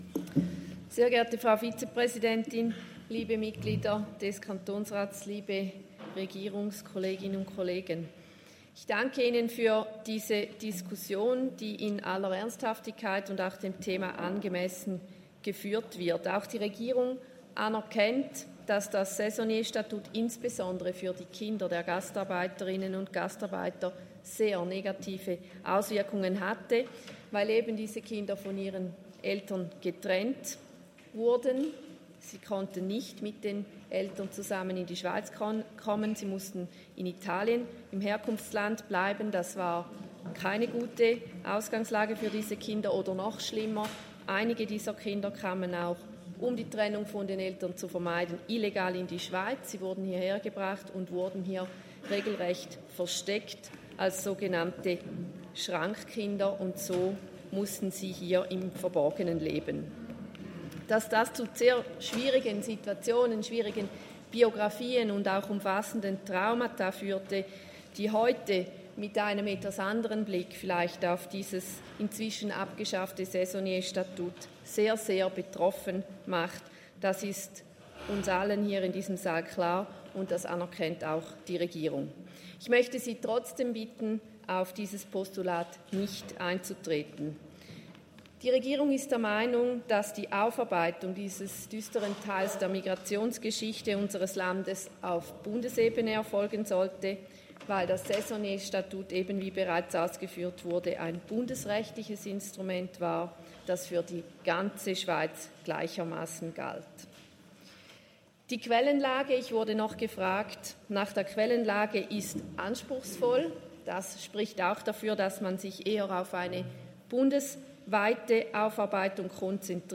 Session des Kantonsrates vom 29. April bis 2. Mai 2024, Aufräumsession
1.5.2024Wortmeldung